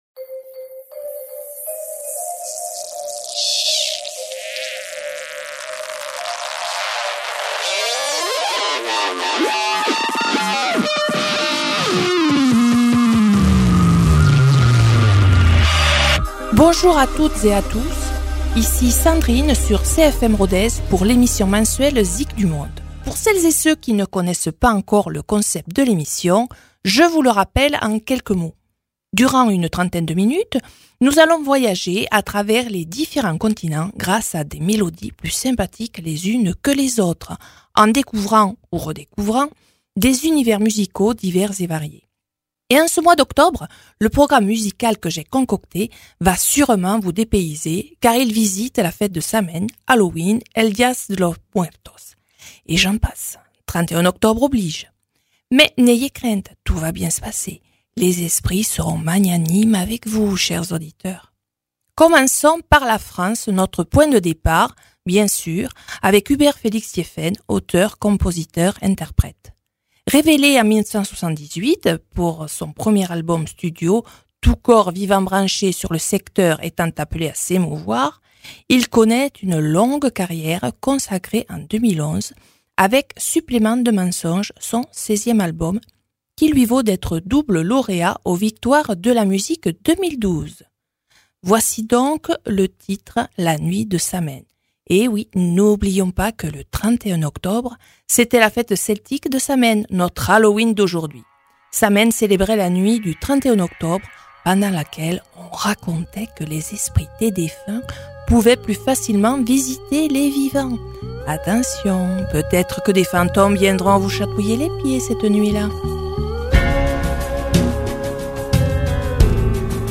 Fêtons cette saison en musique avec de sympathiques mélodies.